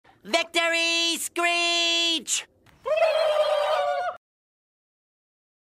Play, download and share Victory Screech.mp3 original sound button!!!!
victory-screech_QvnffKV.mp3